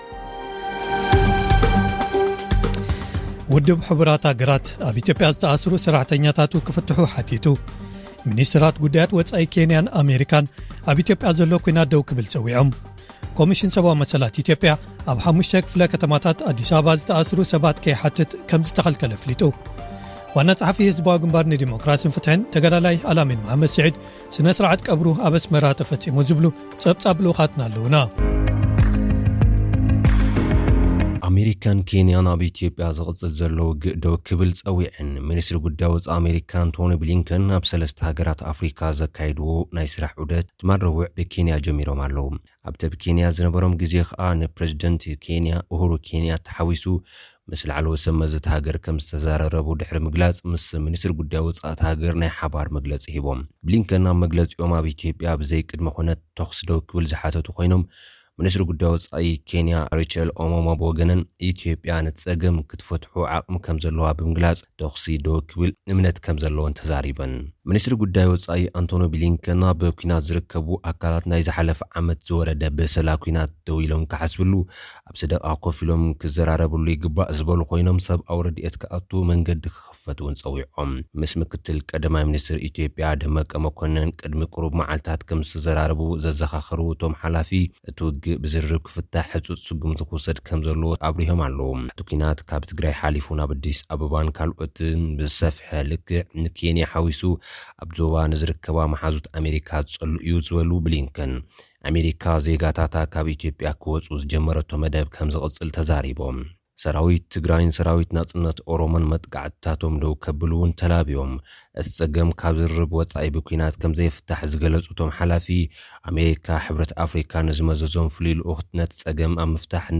ሓደስቲ ጸብጻብ ዜናታት (18/11/2021)